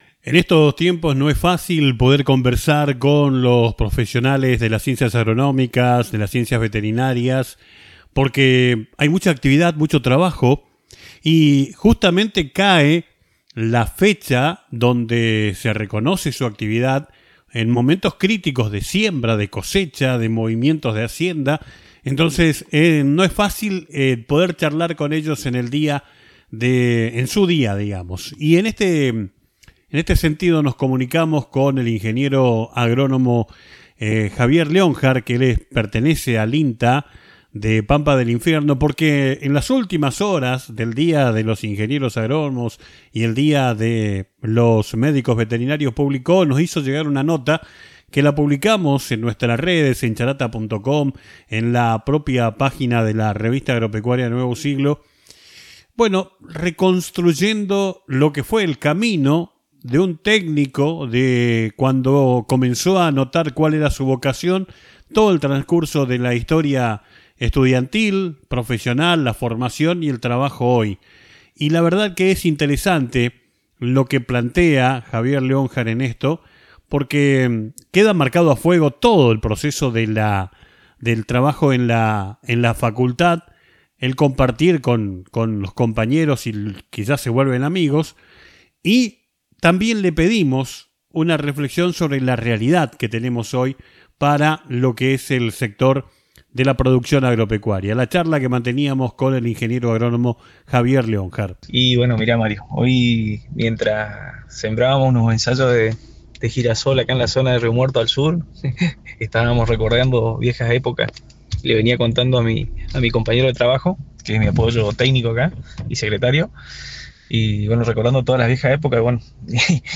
Entrevista realizada para los programas de radiales de la Revista Agropecuaria Nuevo Siglo